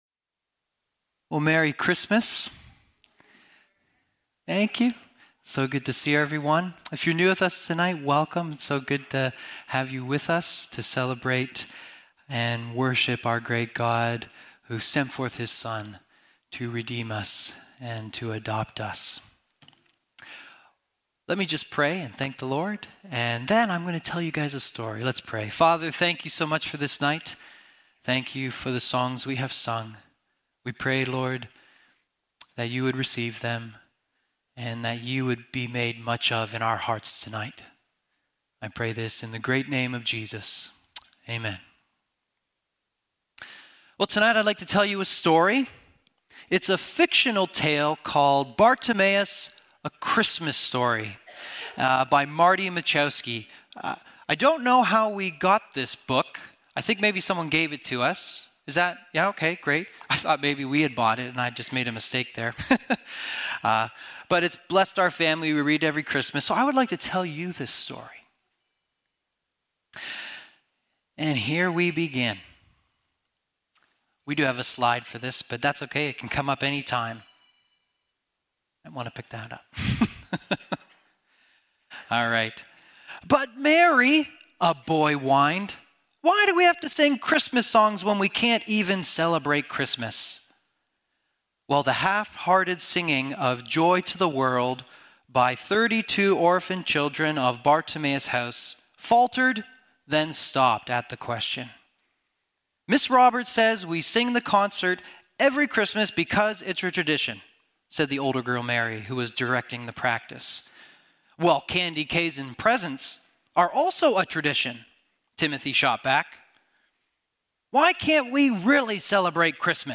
Christmas Eve Service 2025